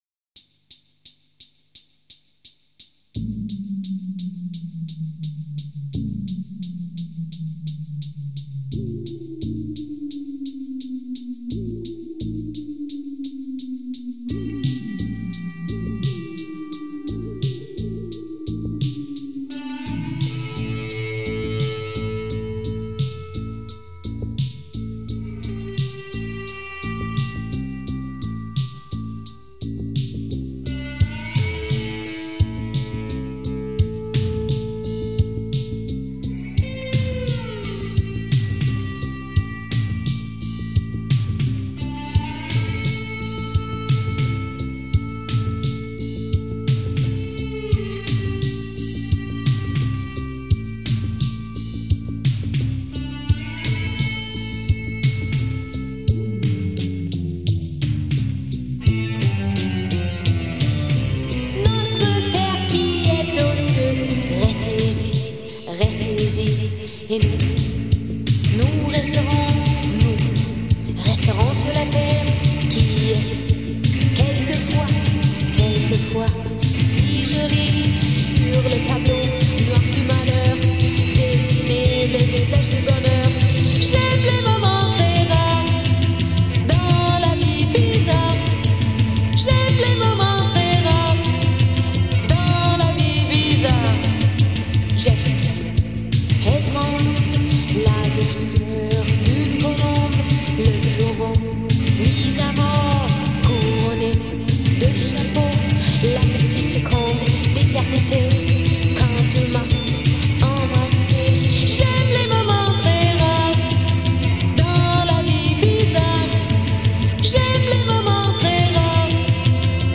Electric Guitar
Vocals